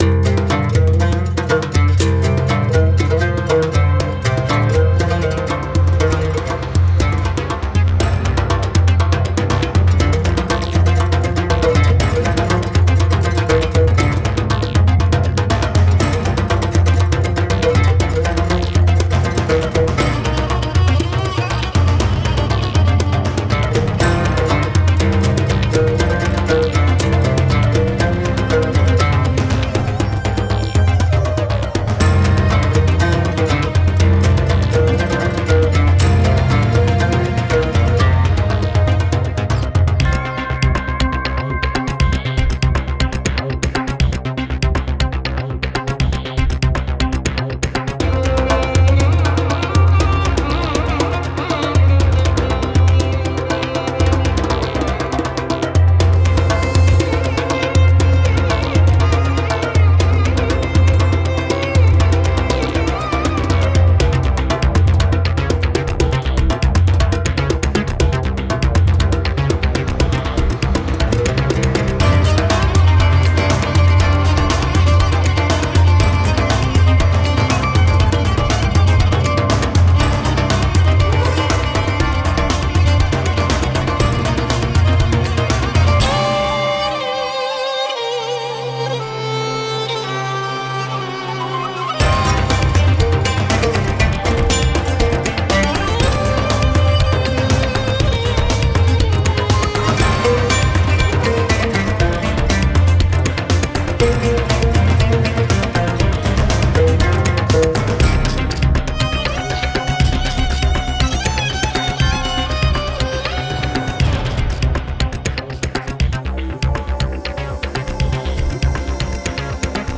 Genre : Hybrid World Music.